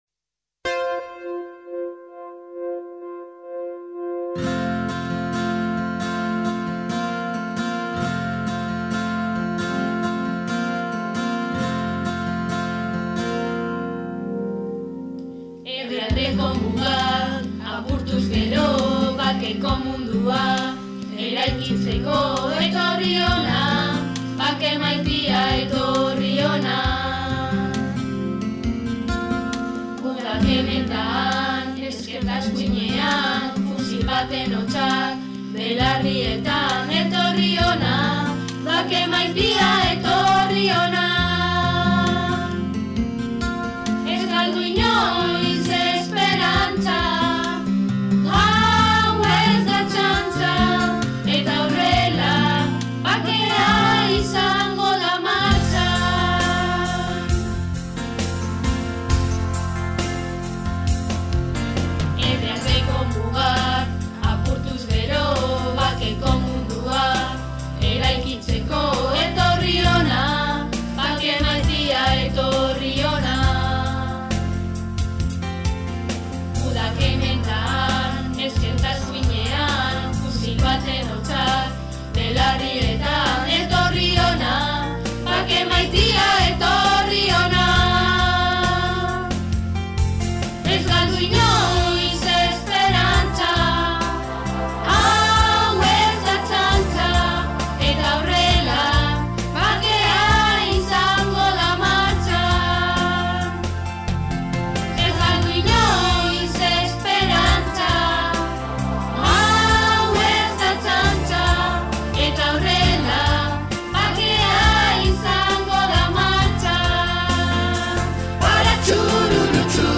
BAKEA 2020 | Judimendi parkean bakearen alde abesten - Escolapios Vitoria Gasteiz